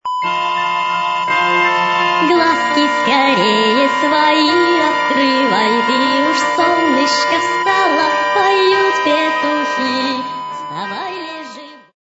• Пример реалтона содержит искажения (писк).